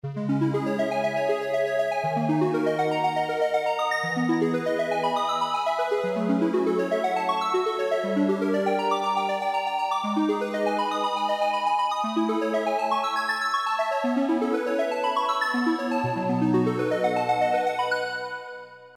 arpeggio square
REVIEW "Fun unit with very lo fidelity sounds. Some very good textures out of PCM and FM syntheses."
arpeggio.mp3